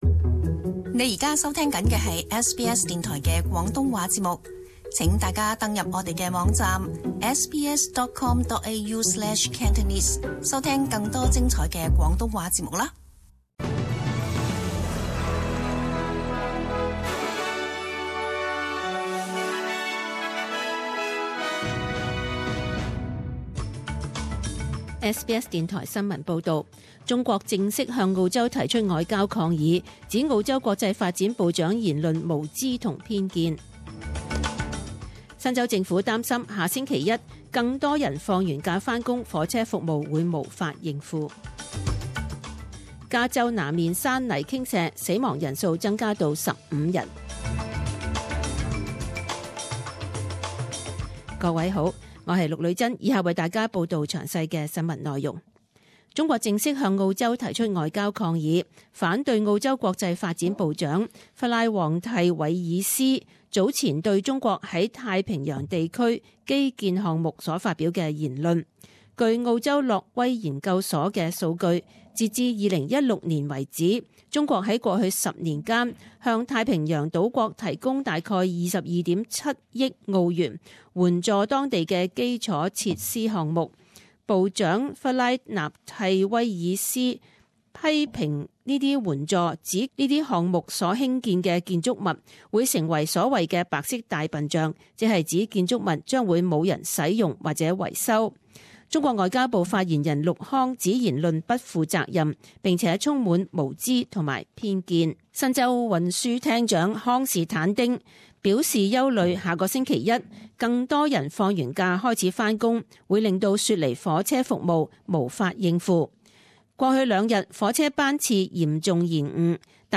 SBS Cantonese 10am news Source: SBS